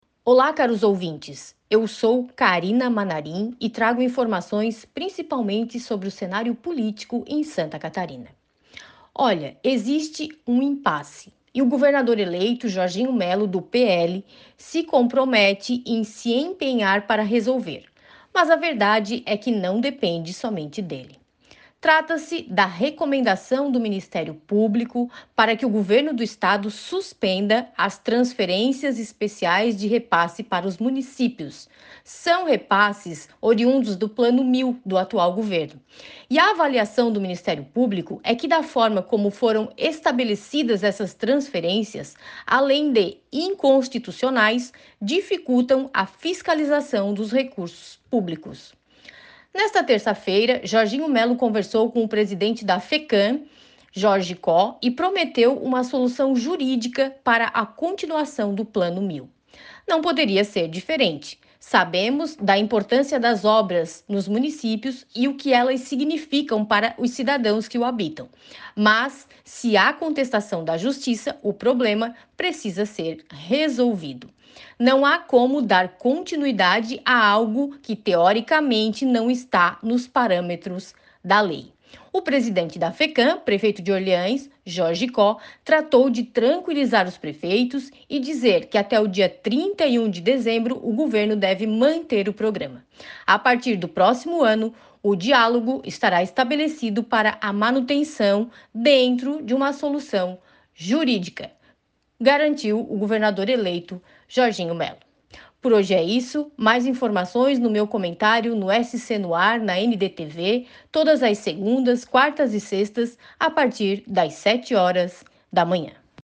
A jornalista ressalta que o Ministério Público de Santa Catarina recomendou a suspensão do repasse de recursos aos municípios catarinenses